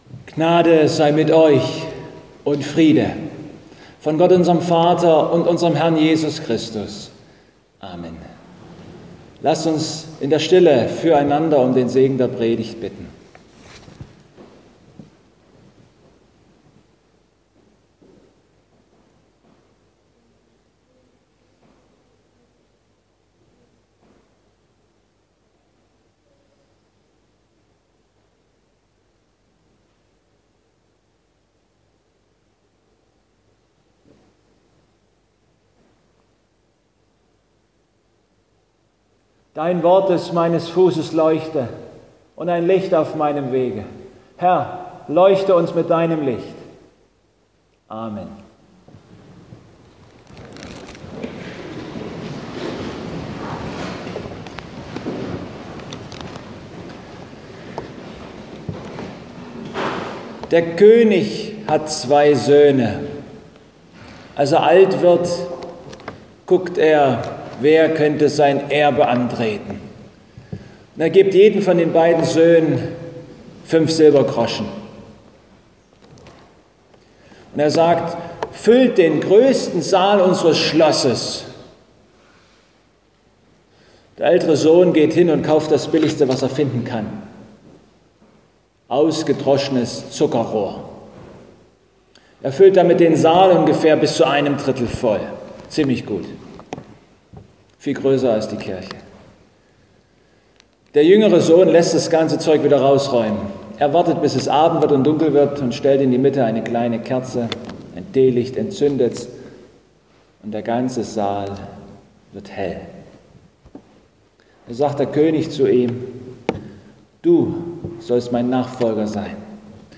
Passage: Joh 8,12 Gottesdienstart: Predigtgottesdienst Im 3.